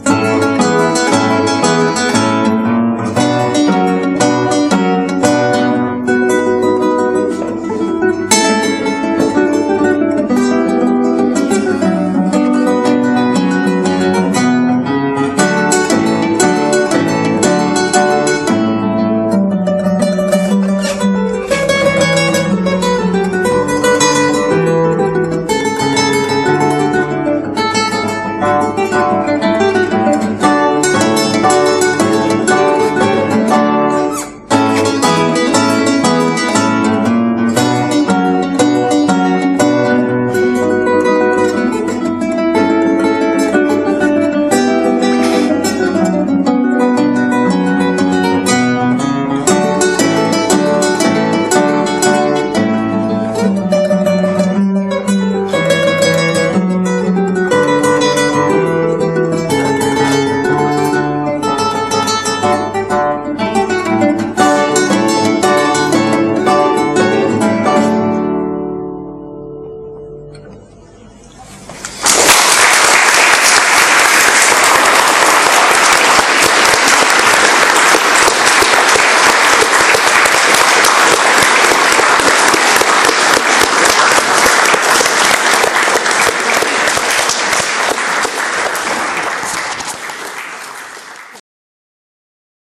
Kytarový recitál v Olomouci
hráče na koncertní kytaru a loutnu
Dvouhodinový koncert, jehož část byla odehrána v úplné tmě, měl velký úspěch, diváci odměnili interprety dlouhotrvajícím potleskem.
Ukázka z kytarového recitálu
koncertu D-dur